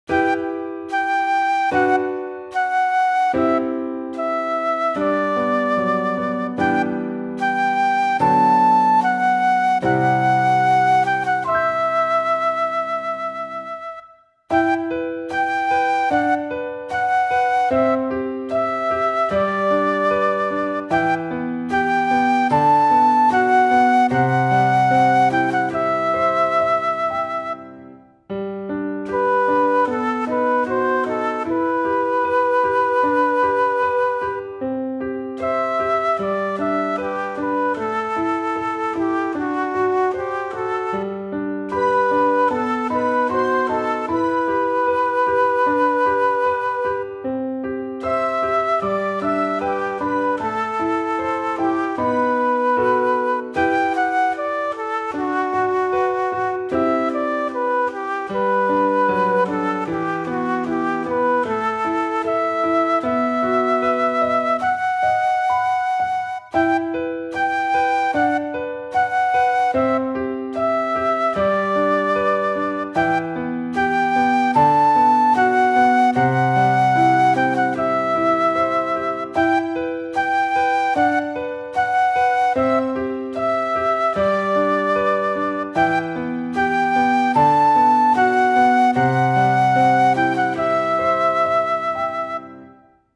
ogg(L) 悲しい しっとり ピアノ フルート